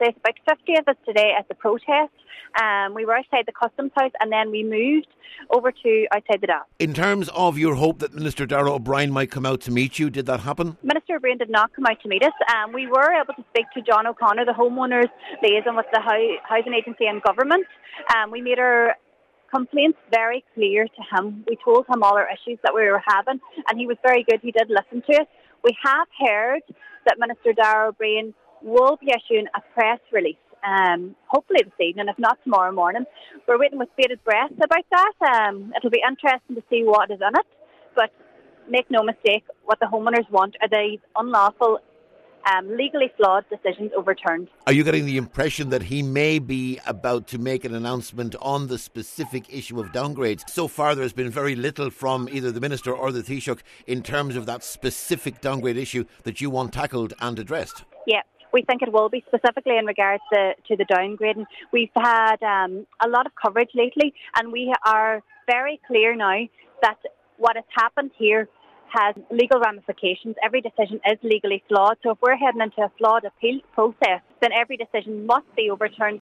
Spokesperson